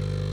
New floppy sound samples